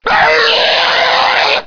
zombie_scream_3.wav